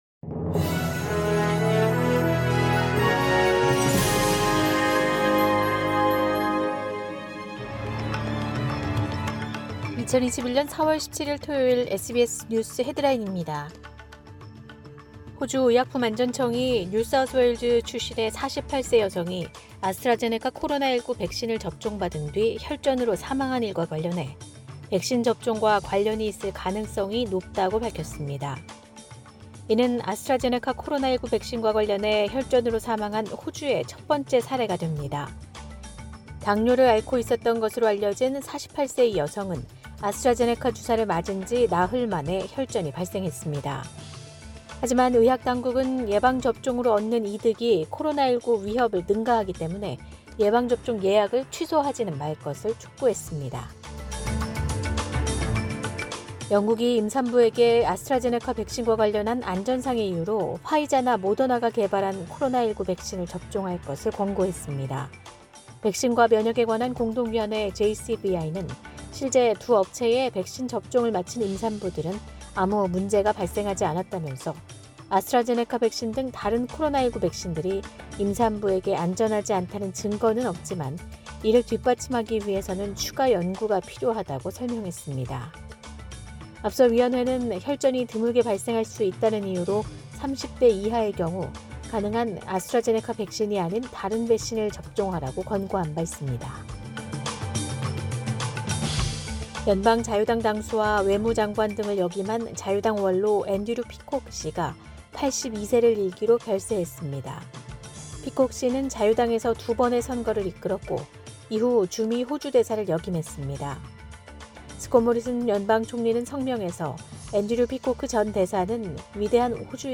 2021년 4월 17일 토요일 SBS 뉴스 헤드라인입니다.